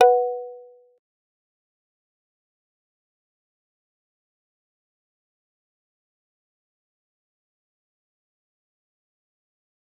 G_Kalimba-B4-pp.wav